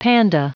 Prononciation du mot panda en anglais (fichier audio)
Prononciation du mot : panda